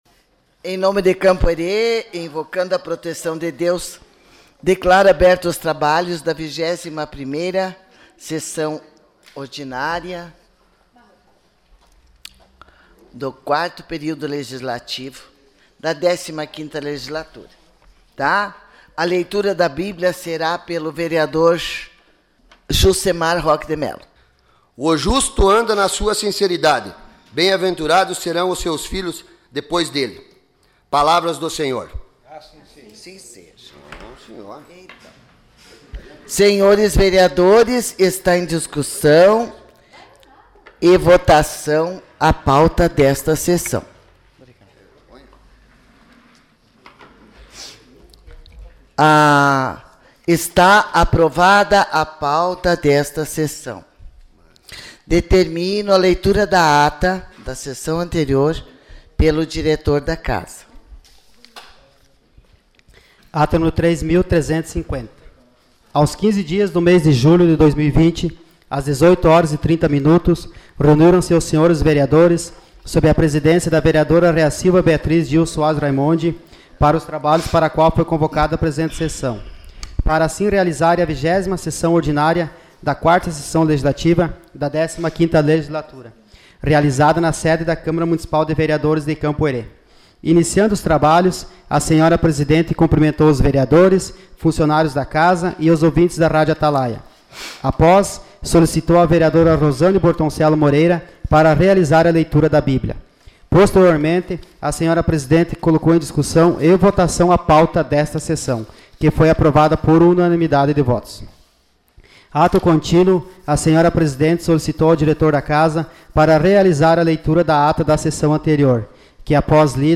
Sessão Ordinária dia 06 de agosto de 2020